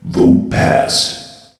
vote_passed.ogg